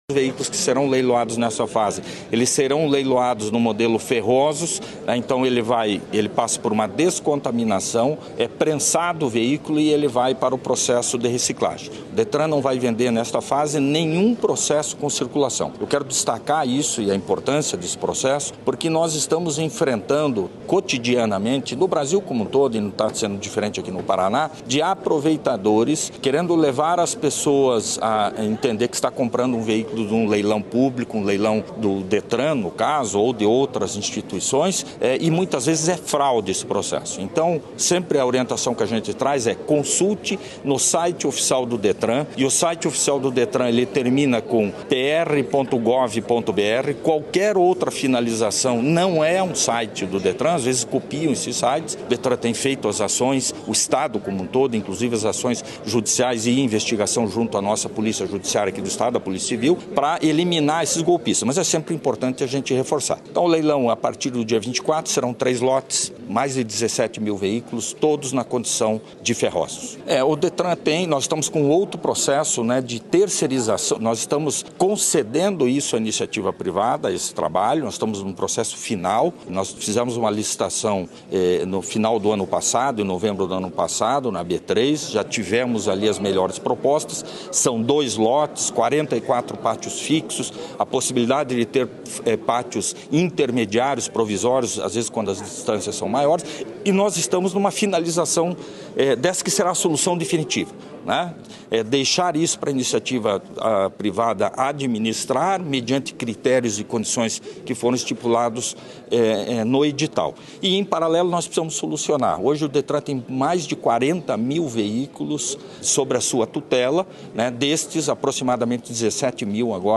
Sonora do diretor-presidente do Detran-PR, Adriano Furtado, sobre o leilão de 17.391 veículos para reciclagem no fim de agosto
ADRIANO FURTADO - LEILAO DE SUCATAS.mp3